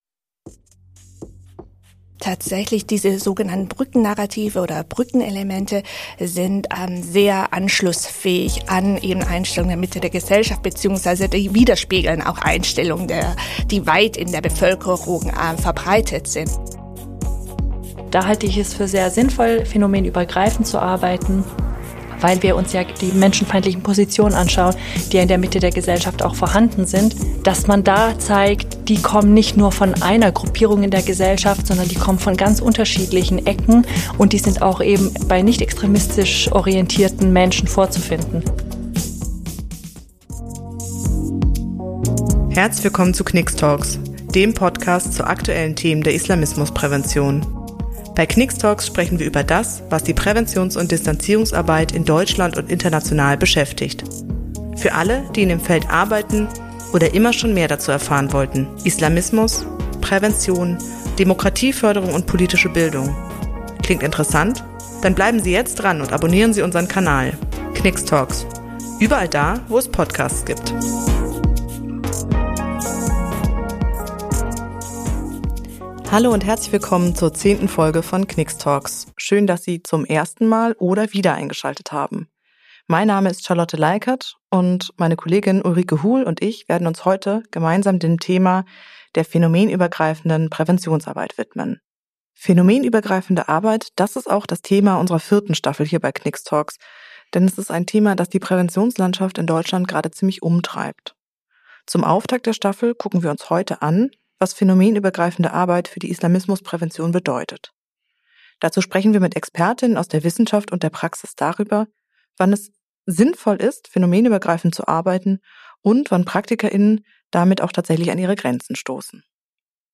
Mit unseren Interviewpartnerinnen sprechen wir unter anderem darüber, welche Chancen, aber auch Grenzen, sie für phänomenübergreifende Arbeit jeweils sehen.